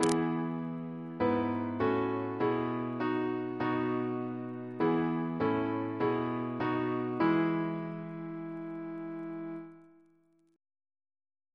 Single chant in E Composer: Parisian Tone Reference psalters: ACB: 50; ACP: 283; OCB: 32; RSCM: 204